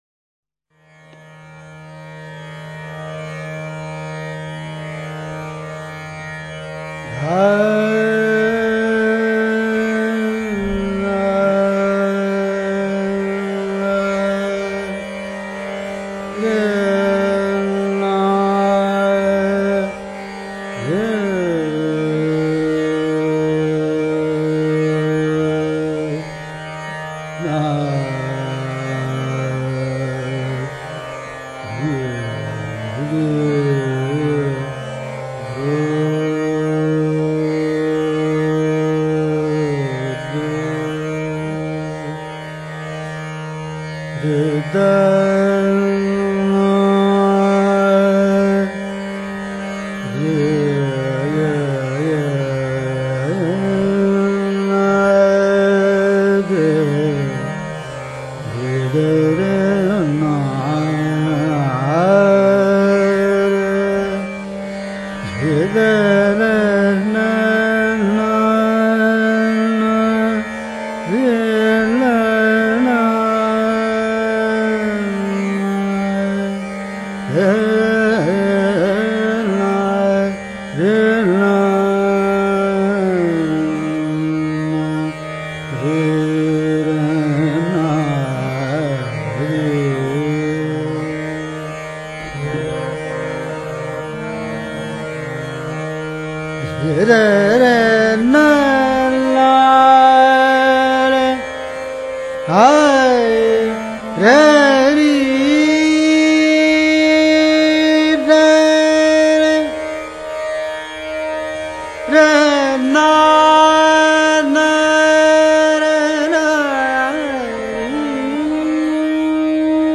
A gold medalist at his post-graduation in music, he was later on trained by Pandit Ram Marathe and Pandit Gajananbua Joshi, stalwarts of Hindustani Classical Music.
Gifted with a melodious voice, with superb flexibility, Kashalkar’s music appeals to every music lover. His gaykee is especially noted for pure traditional rendering with his own asthetic interpretation.